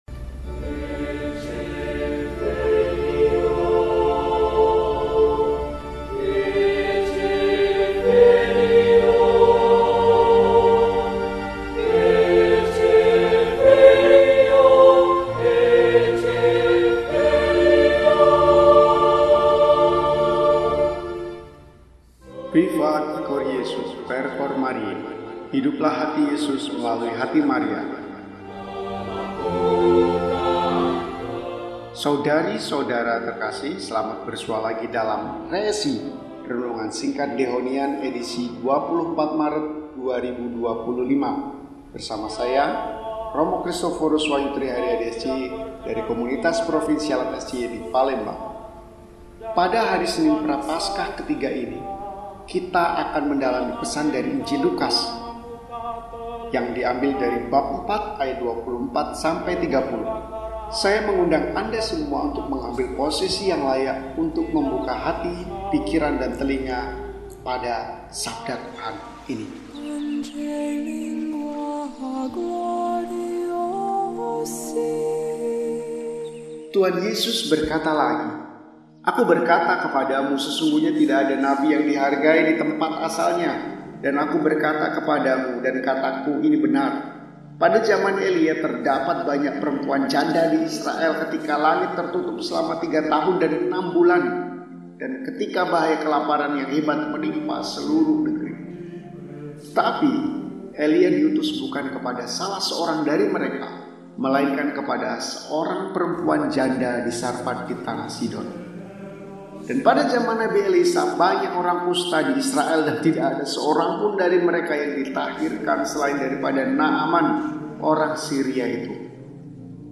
Senin, 24 Maret 2025 – Hari Biasa Pekan III Prapaskah – RESI (Renungan Singkat) DEHONIAN